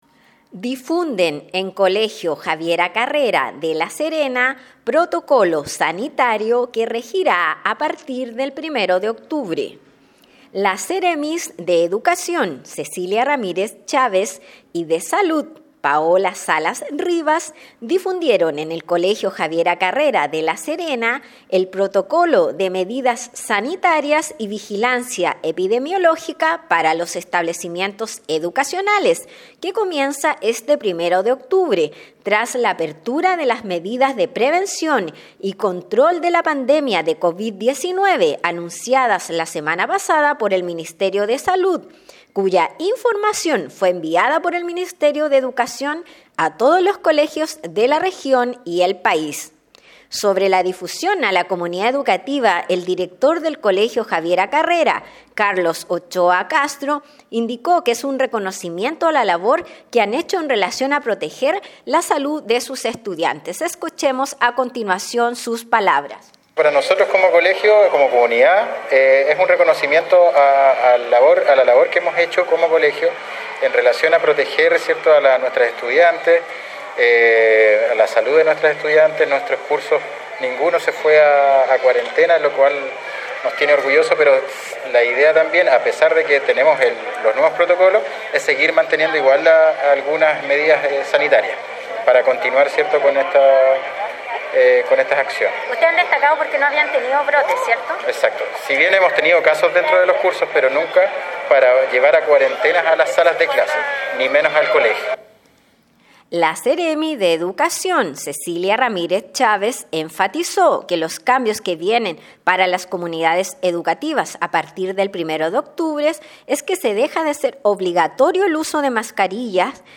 AUDIO : Despacho Difunden en colegio Javiera Carrera Protocolo Sanitario que regirá a partir del 01 de octubre del 2022